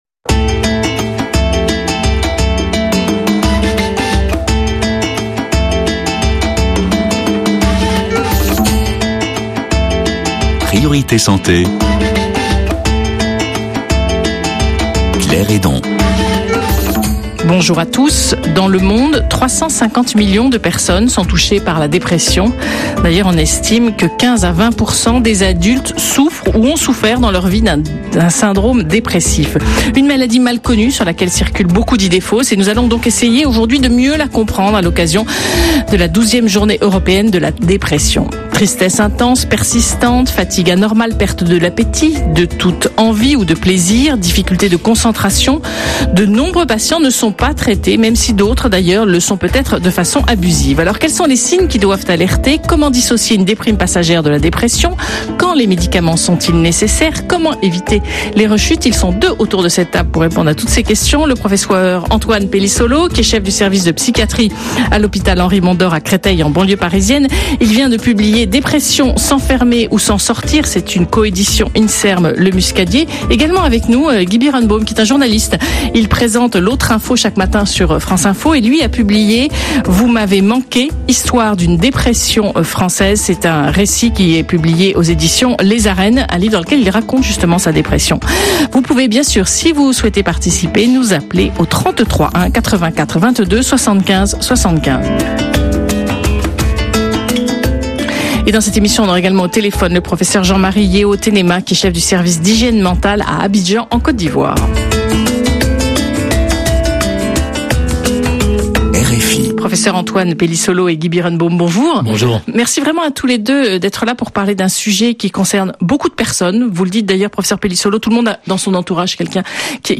Ecoutez le témoignage d'une patiente parler de son état dépressif.